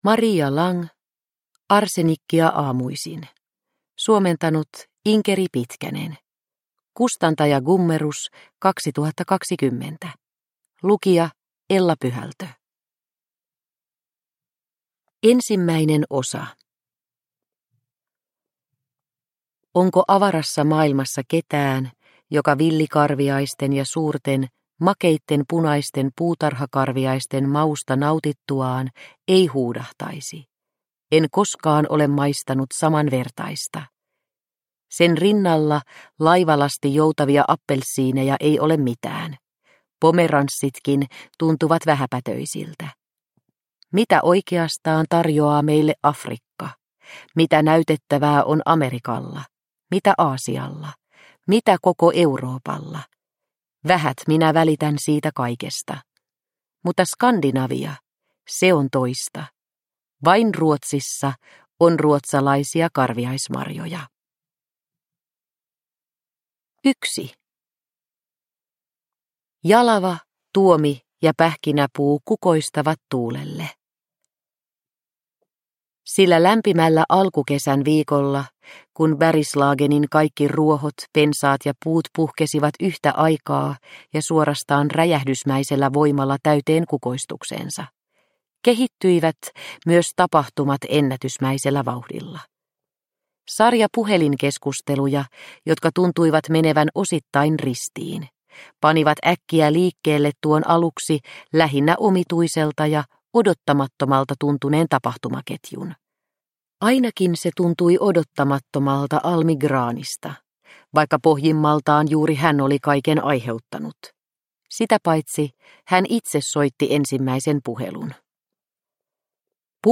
Arsenikkia aamuisin – Ljudbok – Laddas ner